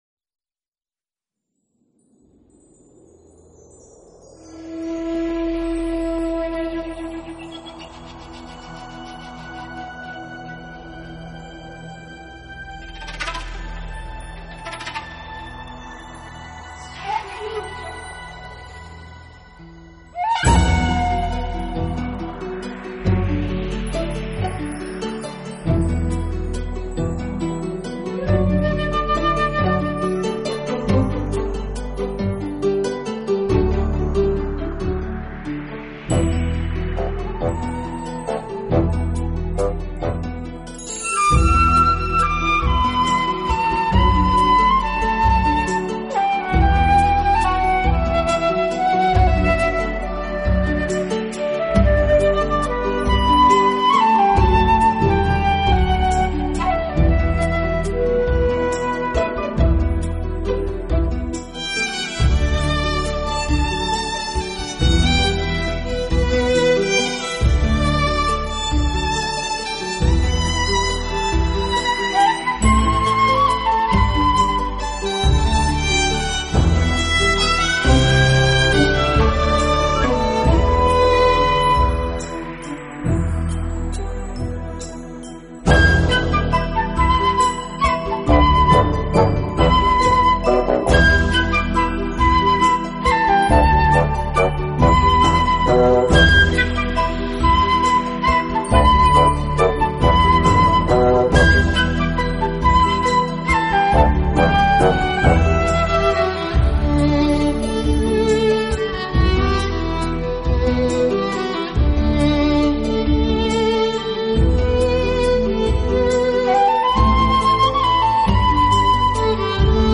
Genre ...........: Meditative
让我们一起放松心情，享受优美音乐带来的浪漫温馨……